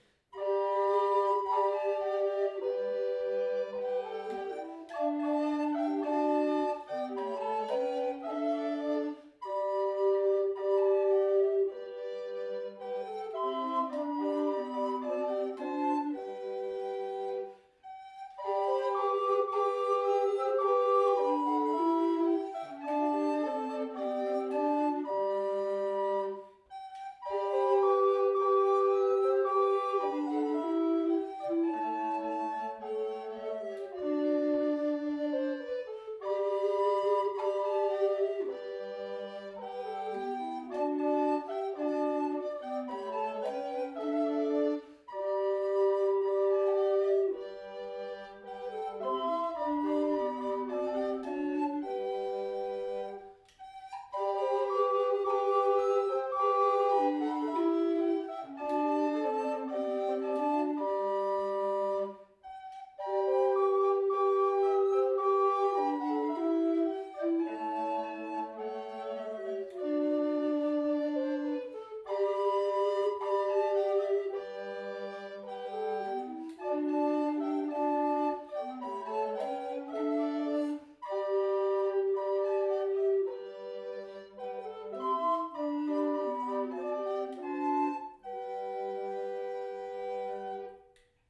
Musik: Flötenspiel